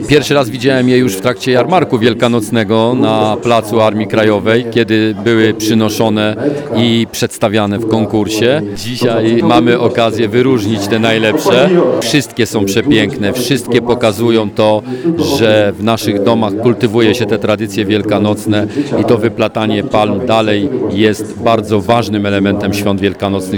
Mówi wicestarosta mielecki Andrzej Bryła.